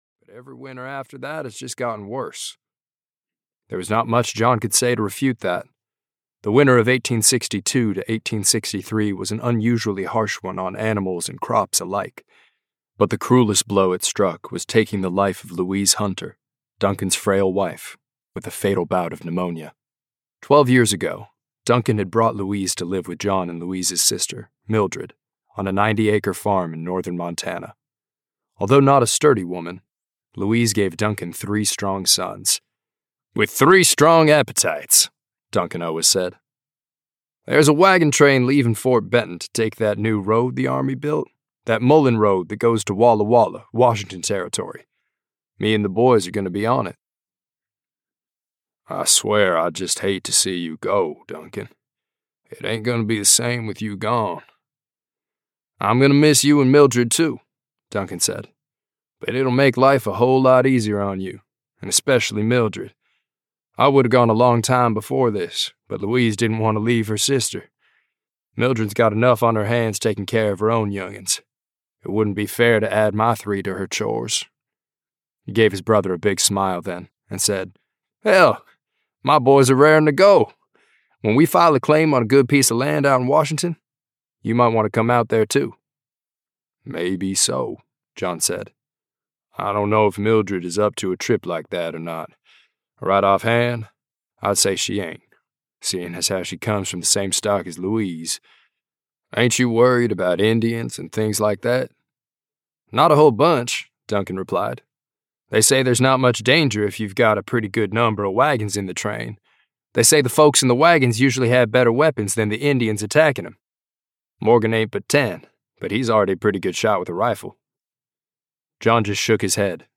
Audio knihaTo Hell and Gone (EN)
Ukázka z knihy